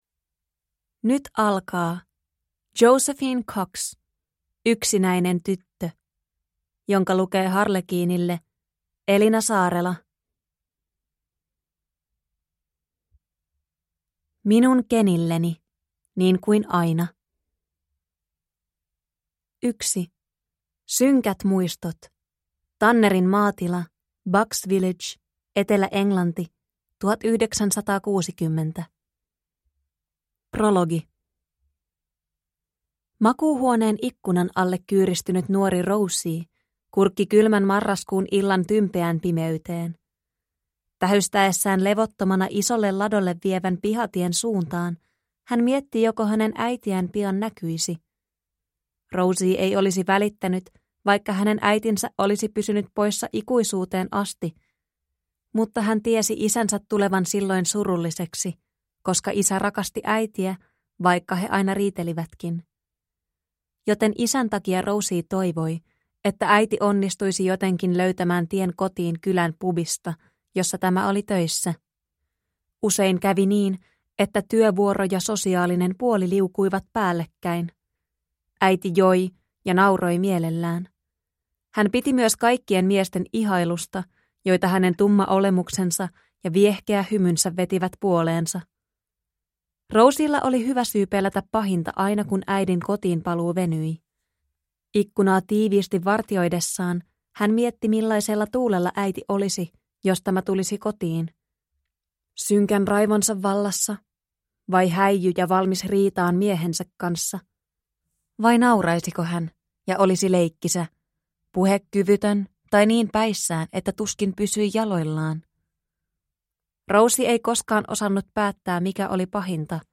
Yksinäinen tyttö – Ljudbok